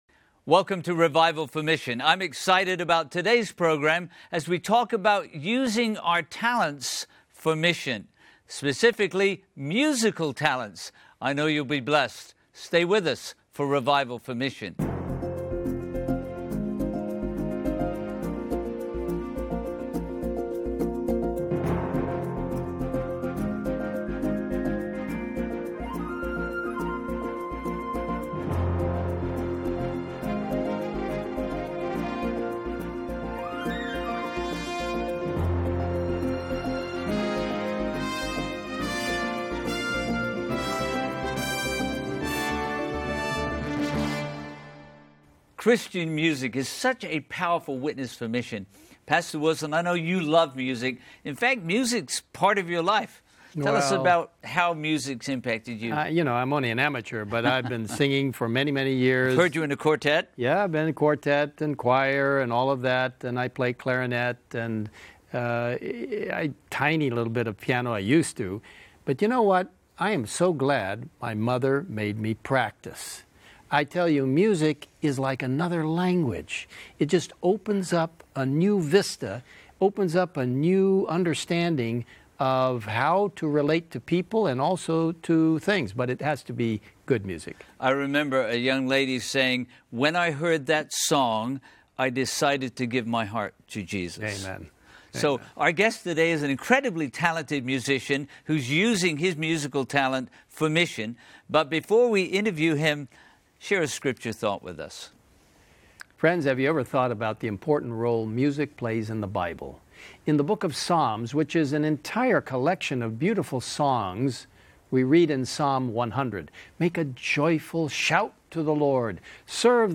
Meet this remarkable young man and hear him play his trumpet on this episode of Revival for Mission.